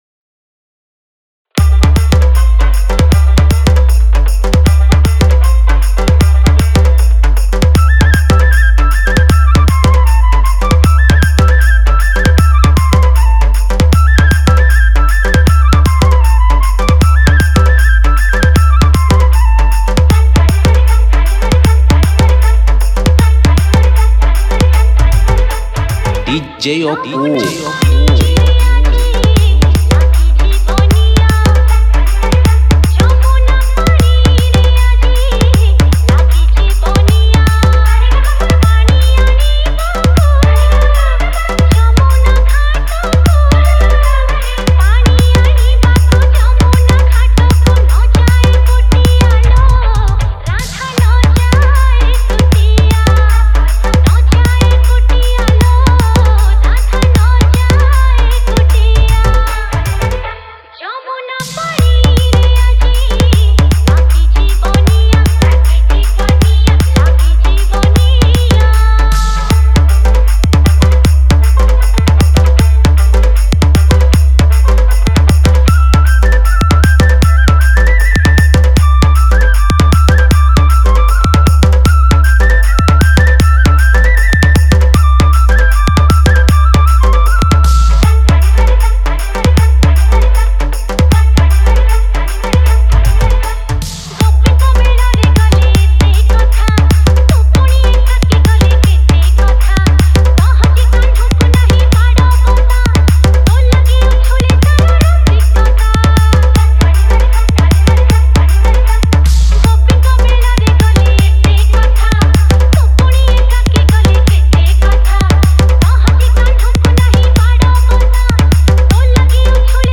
Odia Bhakti Tapori Dance Mix